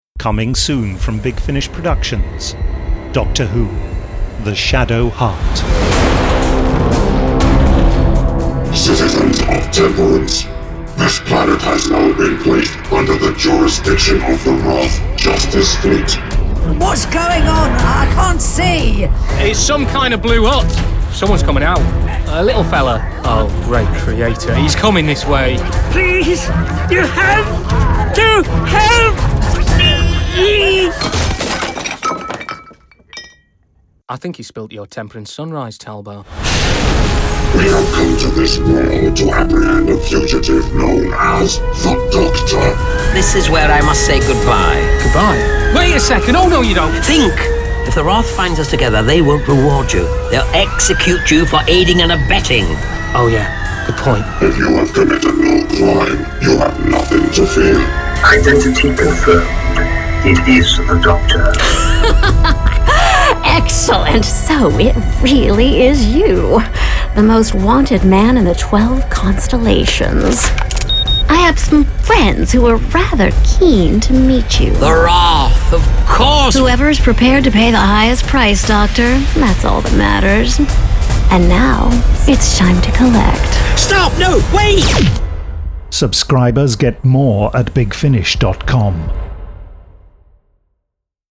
Award-winning, full-cast original audio dramas from the worlds of Doctor Who